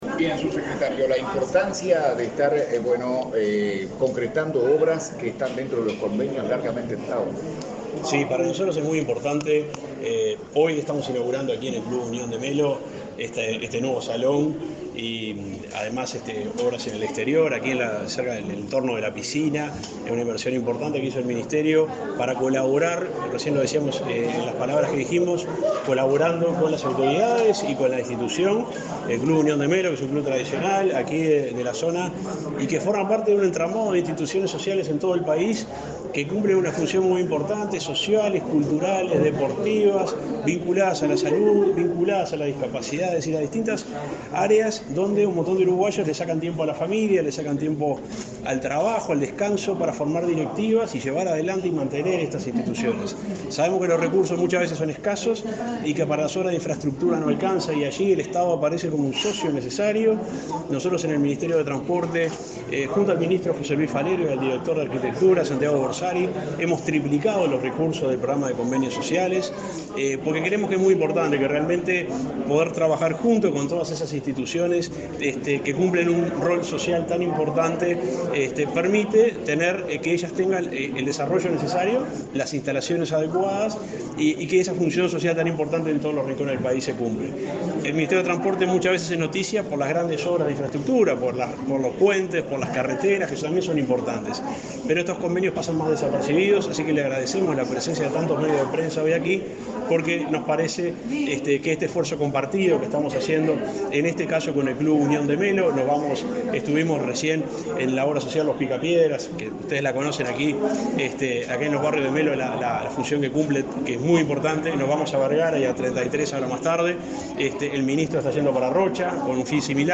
Declaraciones a la prensa del subsecretario de Transporte, Juan José Olaizola
Declaraciones a la prensa del subsecretario de Transporte, Juan José Olaizola 15/12/2022 Compartir Facebook X Copiar enlace WhatsApp LinkedIn El subsecretario de Transporte, Juan José Olaizola, dialogó con la prensa, luego de recorrer los departamentos de Cerro Largo y Treinta y Tres, donde firmó varios convenios con instituciones sociales.